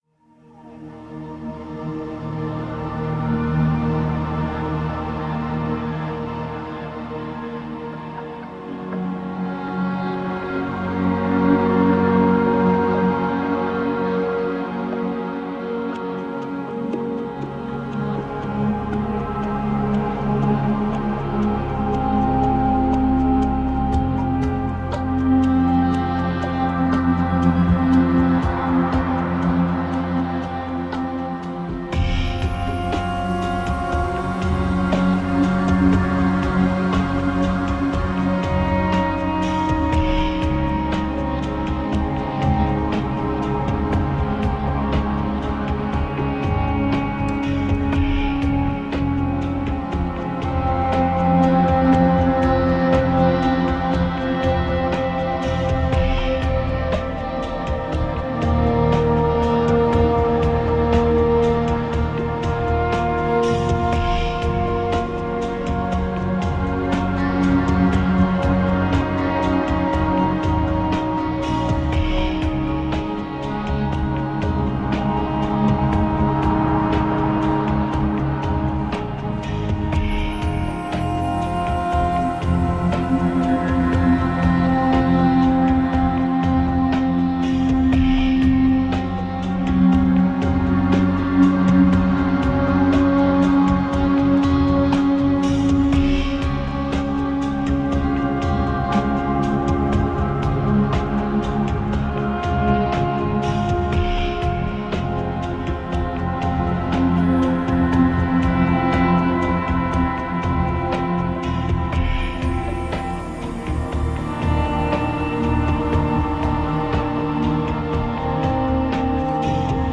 Beautiful ambient space music.
Sensual and rich, like fine dark chocolate,